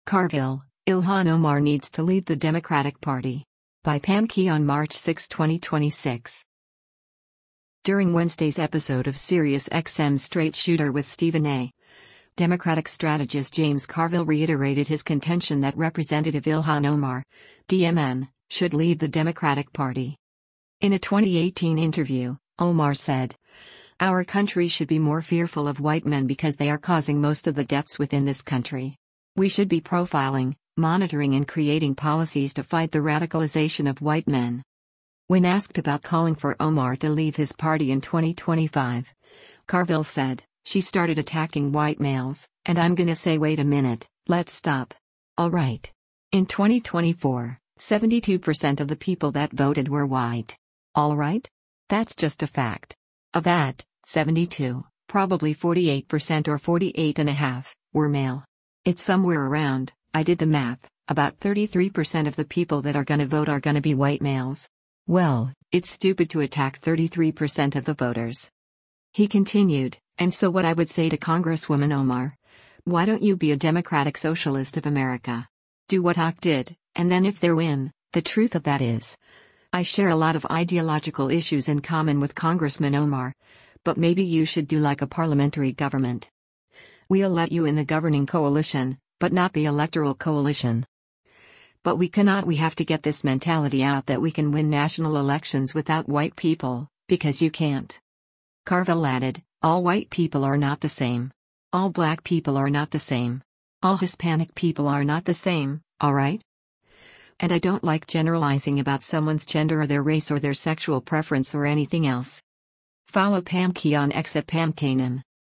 During Wednesday’s episode of SiriusXM’s “Straight Shooter with Stephen A.,” Democratic strategist James Carville reiterated his contention that Rep. Ilhan Omar (D-MN) should leave the Democratic Party.